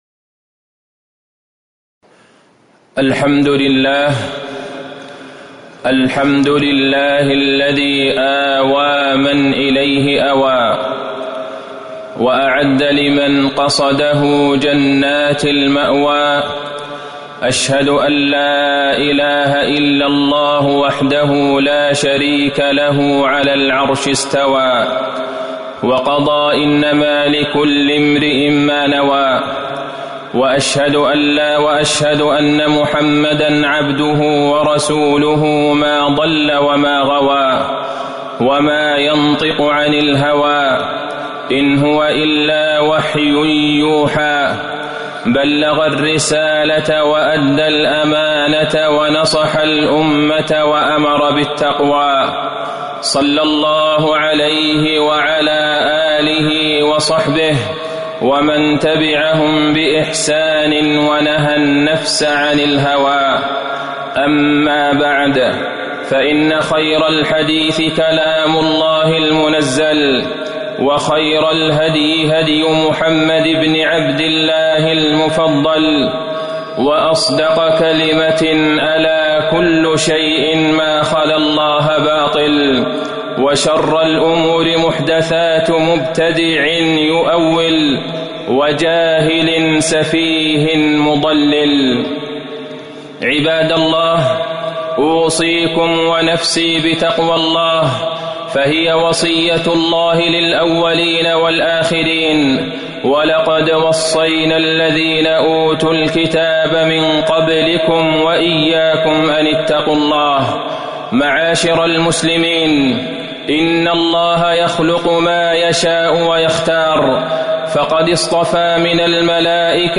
تاريخ النشر ٢٣ ذو القعدة ١٤٤٠ هـ المكان: المسجد النبوي الشيخ: فضيلة الشيخ د. عبدالله بن عبدالرحمن البعيجان فضيلة الشيخ د. عبدالله بن عبدالرحمن البعيجان منسك الحج وحرمة موسمه